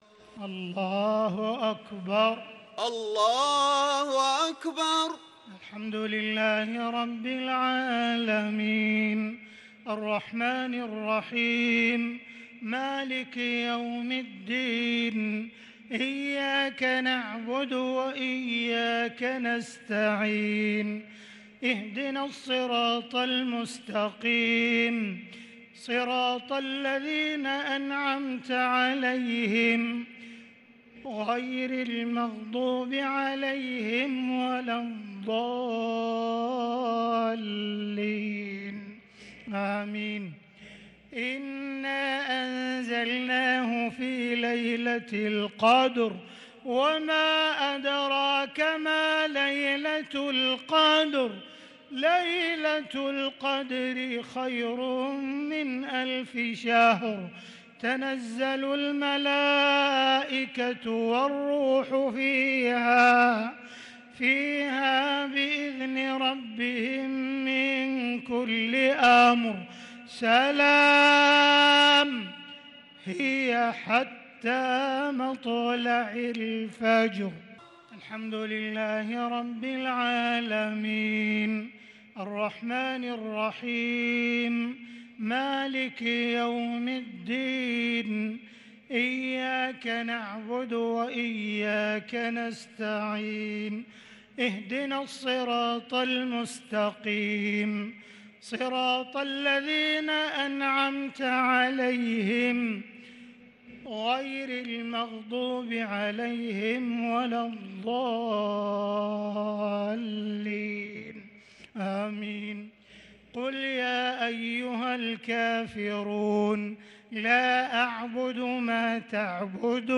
الشفع و الوتر ليلة 27 رمضان 1443هـ | Witr 27 st night Ramadan 1443H > تراويح الحرم المكي عام 1443 🕋 > التراويح - تلاوات الحرمين